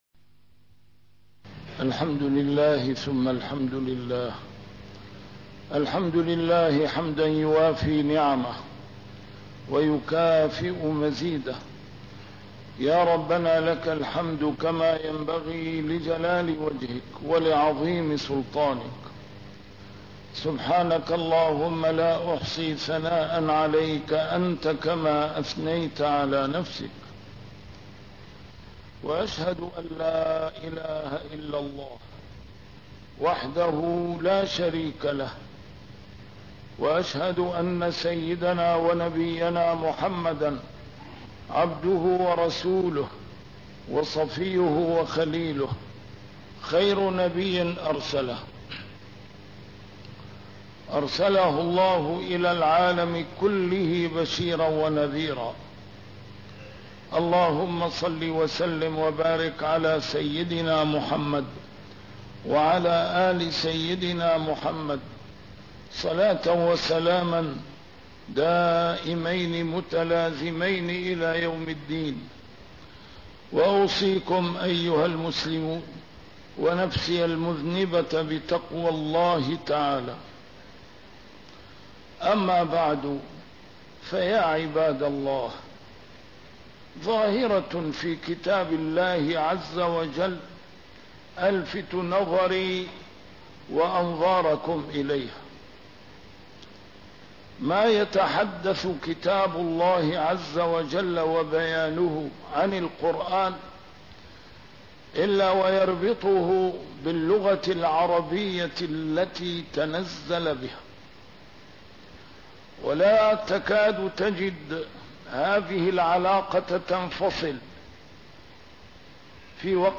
A MARTYR SCHOLAR: IMAM MUHAMMAD SAEED RAMADAN AL-BOUTI - الخطب - اللغة العربية بين غزو الأعداء وحصون الأمناء